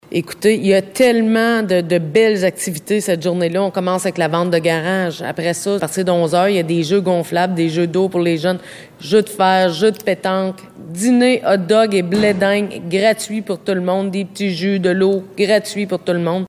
La mairesse de Déléage et membre du comité des loisirs, Anne Potvin, en dit plus sur ce que sera cette grande journée :